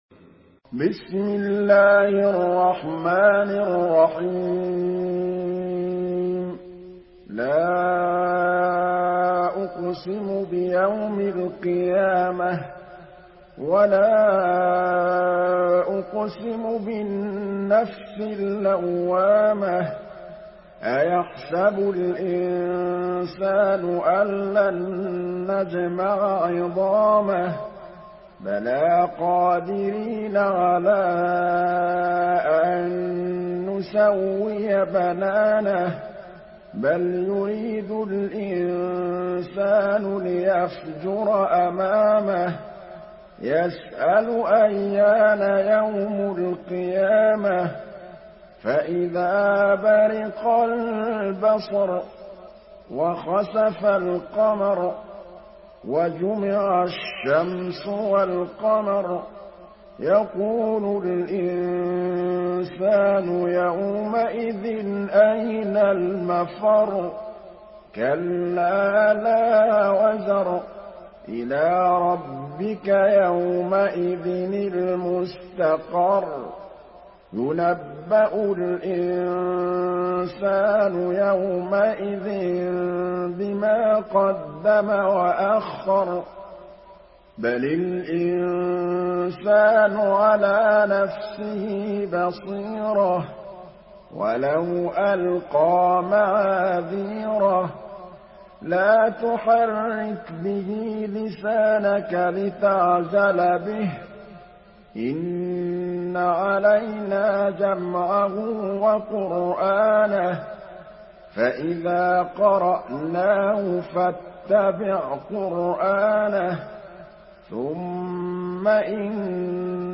Surah আল-ক্বিয়ামাহ্‌ MP3 by Muhammad Mahmood Al Tablawi in Hafs An Asim narration.
Murattal Hafs An Asim